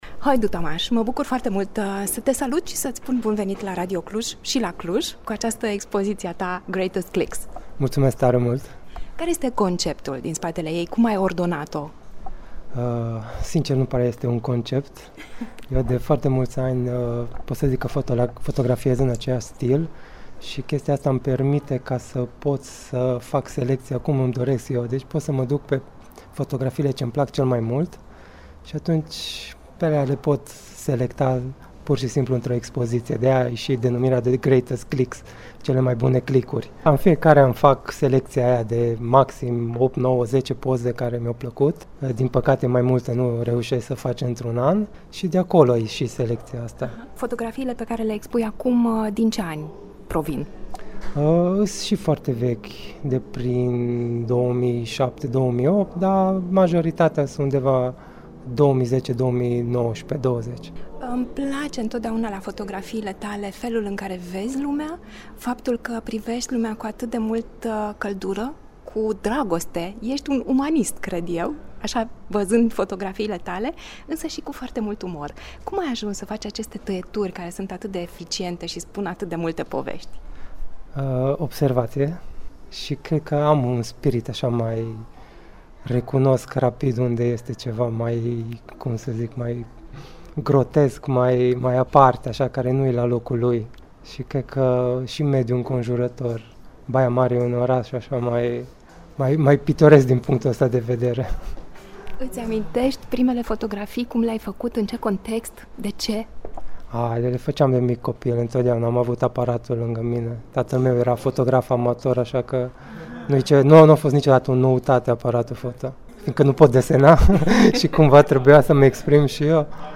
El a acordat un interviu pentru Radio Cluj, îl puteți asculta aici: Expoziția ”Greatest Clicks” rămâne deschisă la Muzeul de Artă până duminică, 22 iunie.